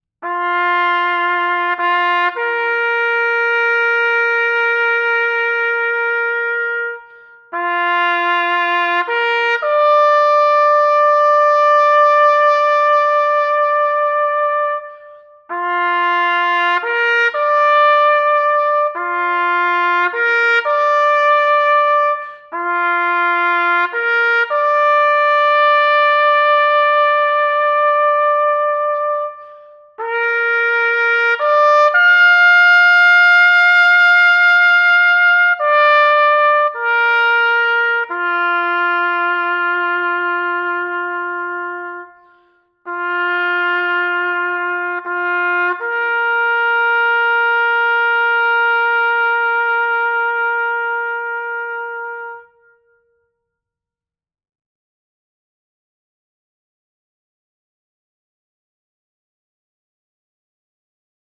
a full-time professional trumpet player in a premier military band.
It’s a melody, a tune I guess.